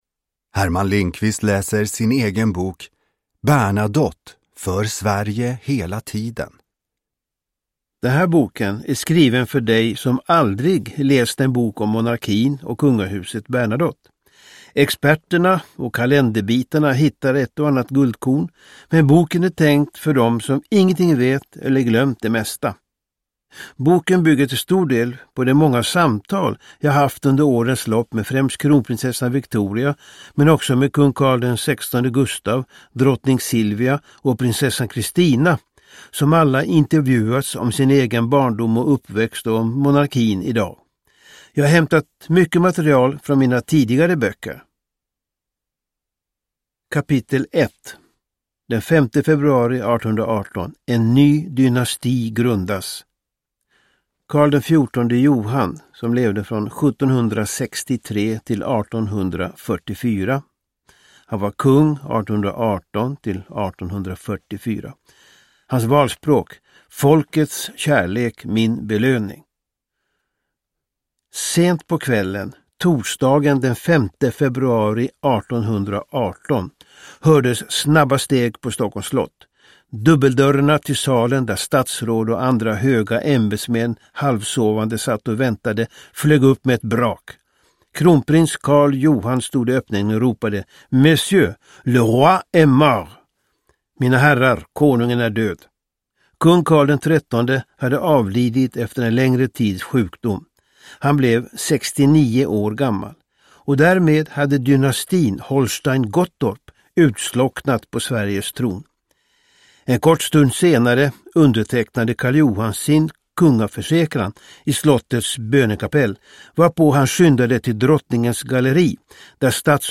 Uppläsare: Herman Lindqvist
Ljudbok